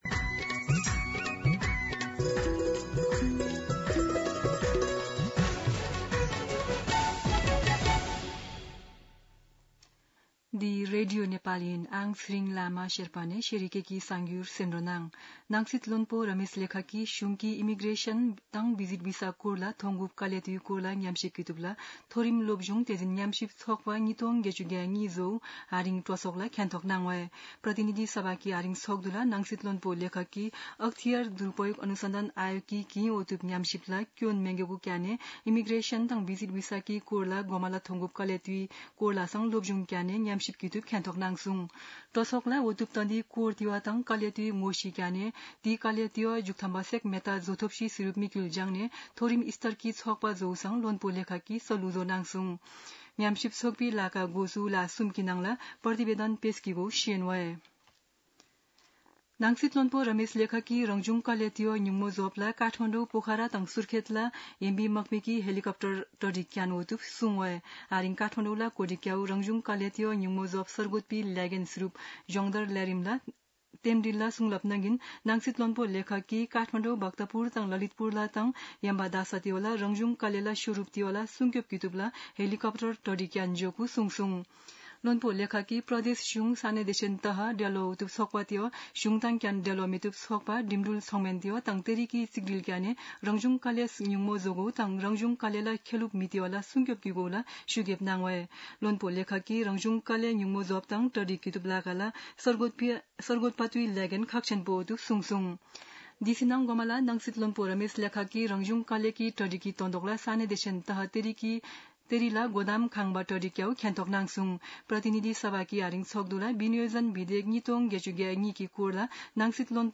शेर्पा भाषाको समाचार : १० असार , २०८२
Sherpa-News-10.mp3